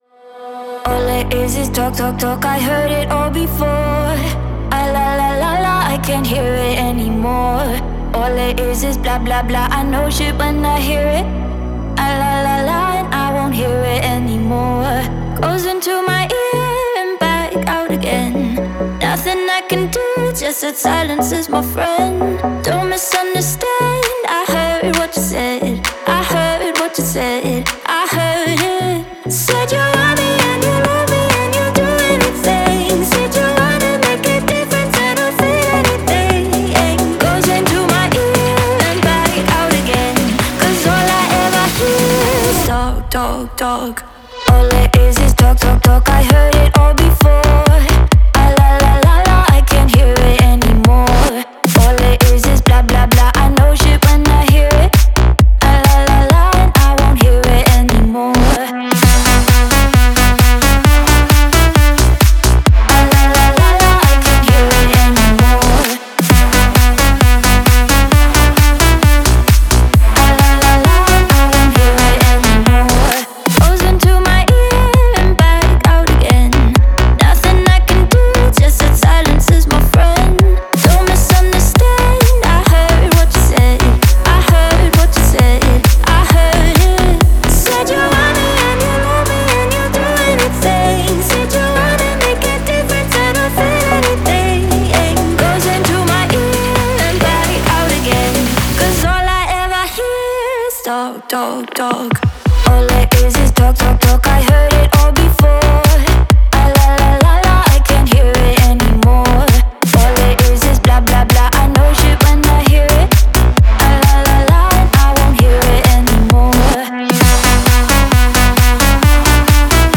энергичная поп-электронная композиция